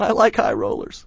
gutterball-3/Gutterball 3/Commentators/Bill/b_ilikehirollers.wav at f3327c52ac3842ff9c4c11f09fb86b6fc7f9f2c5